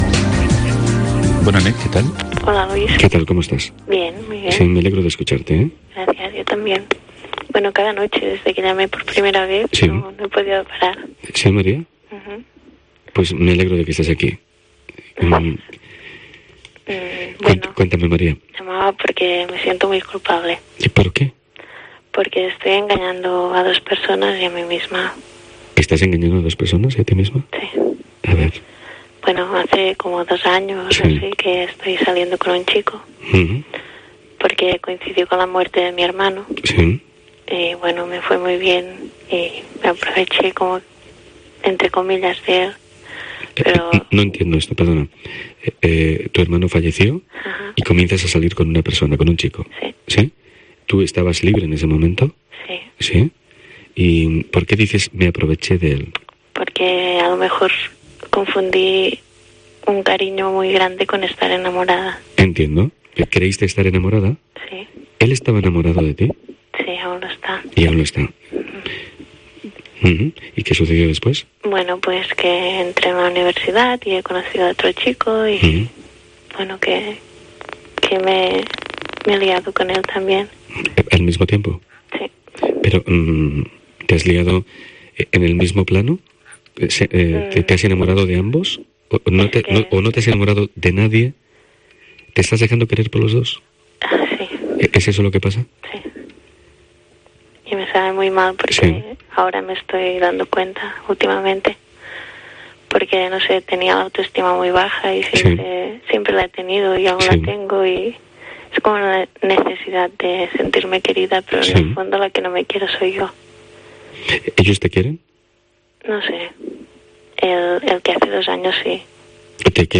La radio a oscuras...